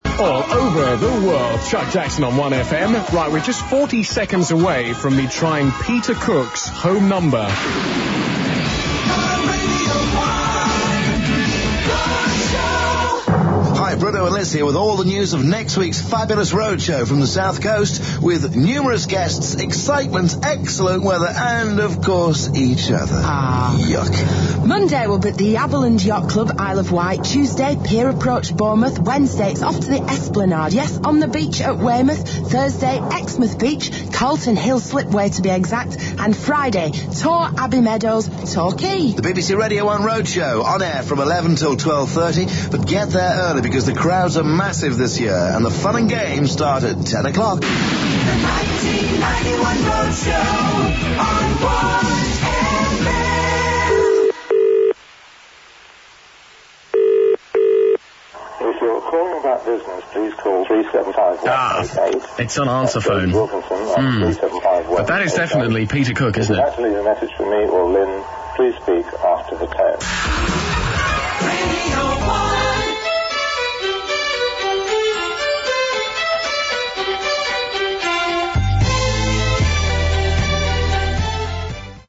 With his unmistakably well moderated and concise voice, Paul was at the station for almost two years.